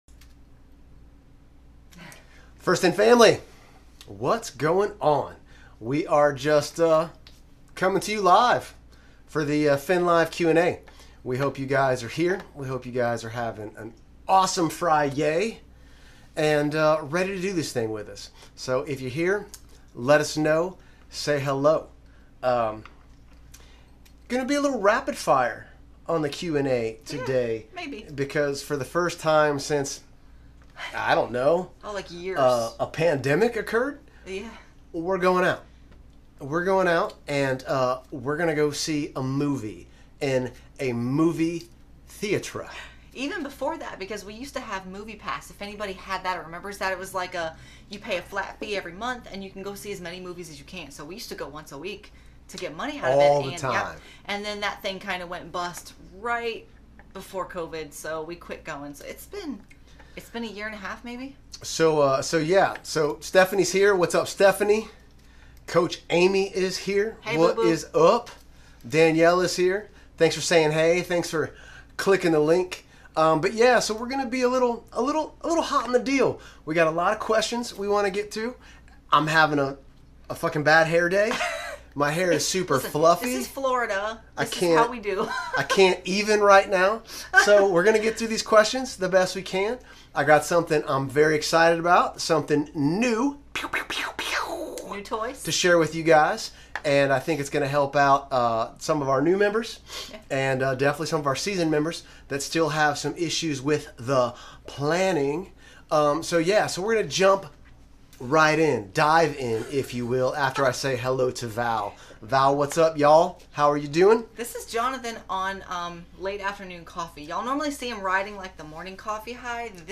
WARNING: I may have been HEAVILY caffeinated and EXTRA SPICY on this one, but we did release an AWESOME NEW FEATURE so that made it ok right?!??